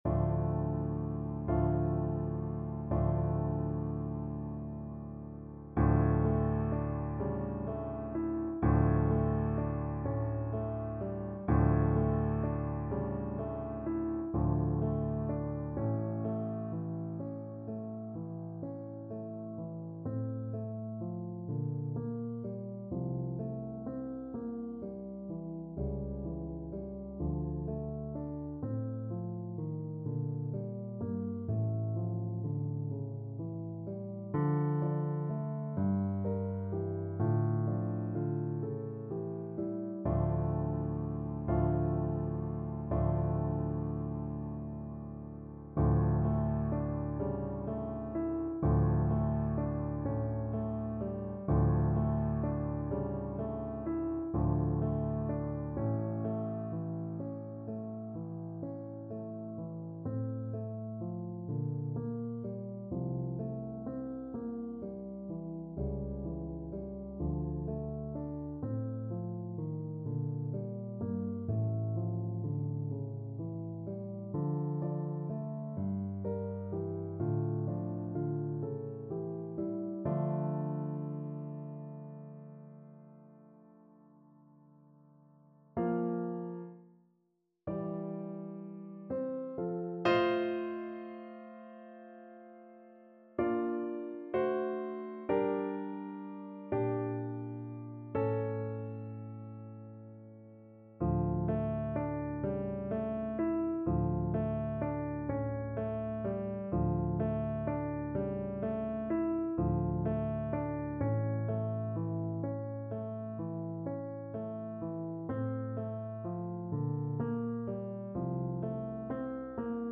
Play (or use space bar on your keyboard) Pause Music Playalong - Piano Accompaniment Playalong Band Accompaniment not yet available transpose reset tempo print settings full screen
6/8 (View more 6/8 Music)
= 42 Andante con moto (View more music marked Andante con moto)
D minor (Sounding Pitch) E minor (Clarinet in Bb) (View more D minor Music for Clarinet )